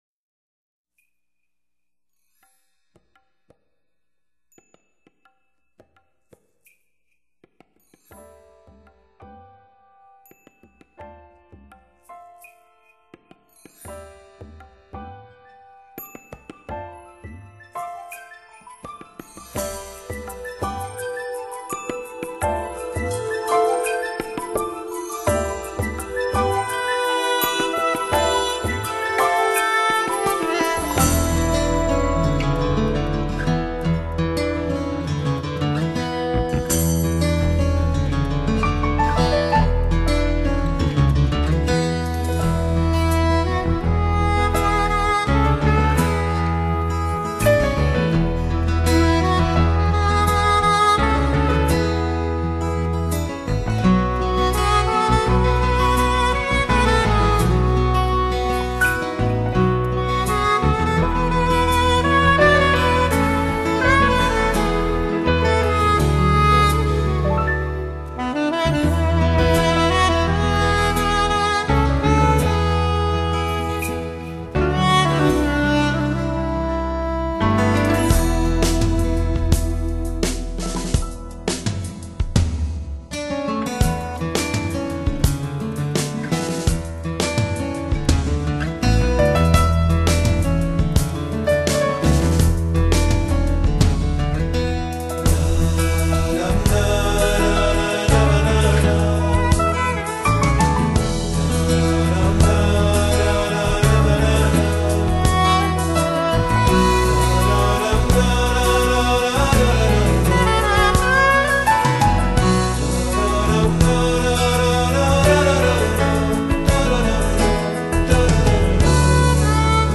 千篇一律的聖誕發燒錄音傳統，管弦樂團與爵士樂隊和諧地合譜出不落俗套的經典聖誕名曲，錄音絕對敢稱同類之冠。
所有的發燒友都會喜歡上它奢華的音樂、透明的音響效果以及創造的活潑氣氛。